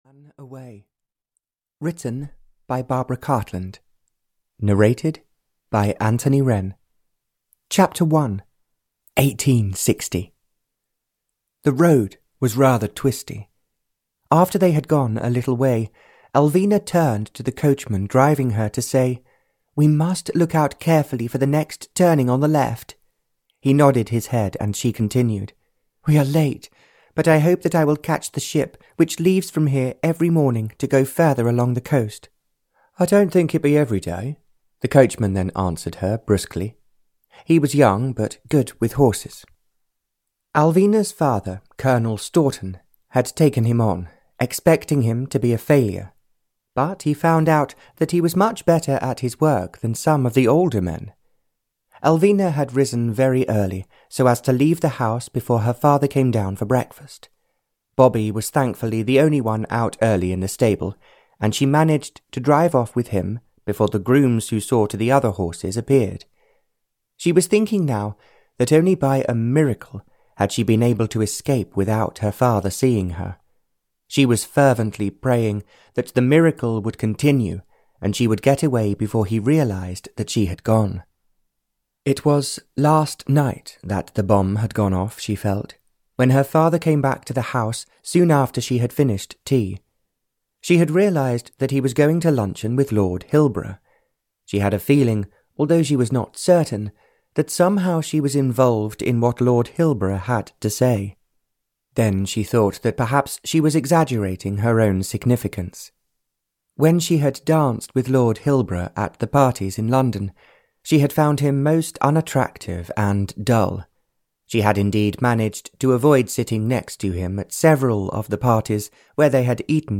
They Ran Away (Barbara Cartland's Pink Collection 149) (EN) audiokniha
Ukázka z knihy